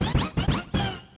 Play, download and share Yoshi Win 64 original sound button!!!!